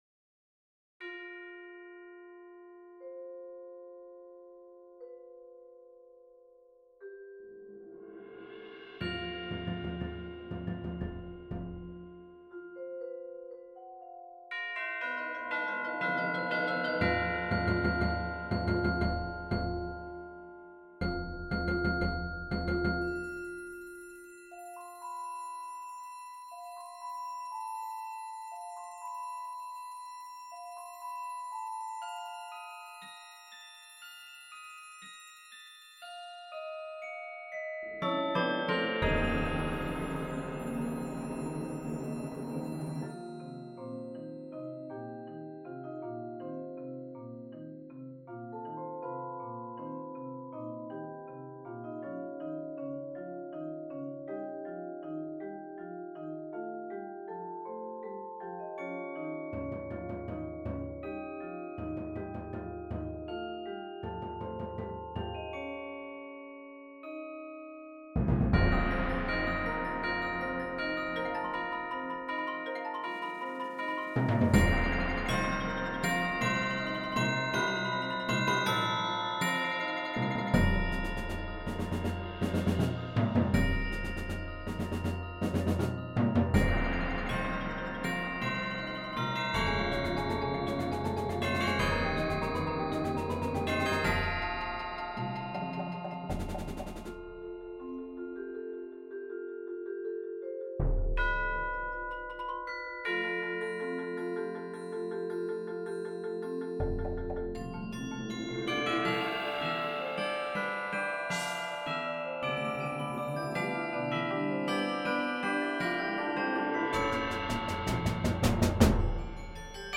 Voicing: Concert Percussion